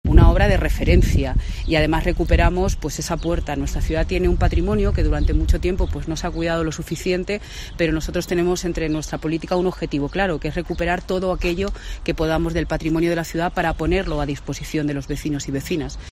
Pilar Zamora, alcaldesa de Ciudad Real